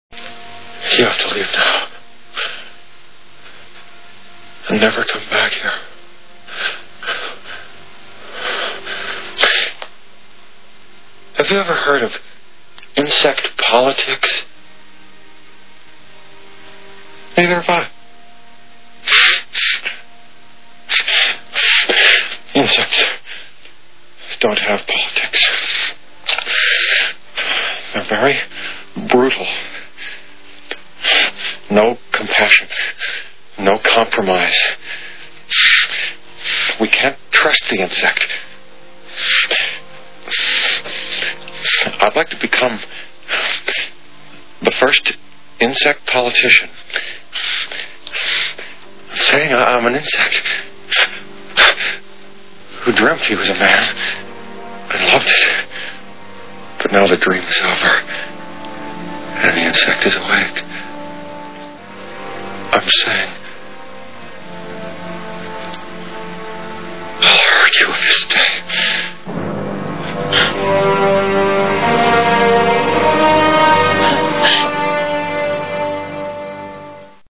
The Fly Movie Sound Bites